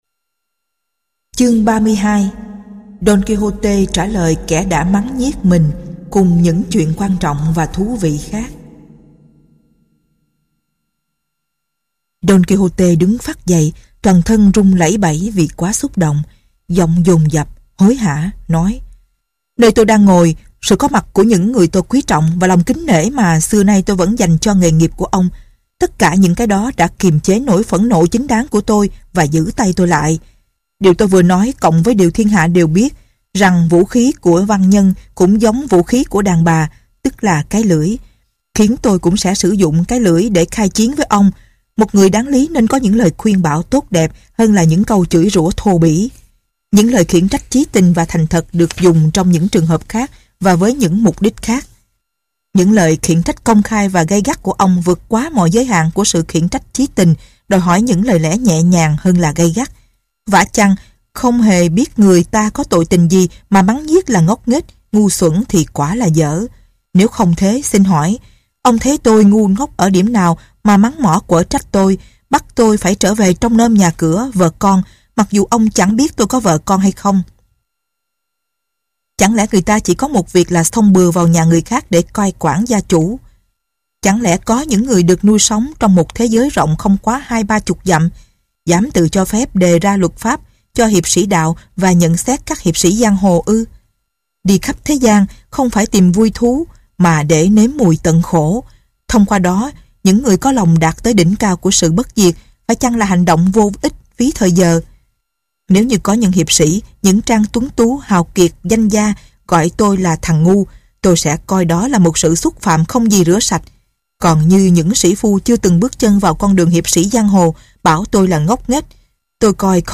Sách nói Đôn KiHôTê nhà quý tộc tài ba xứ Mantra tập 2 - Sách Nói Online Hay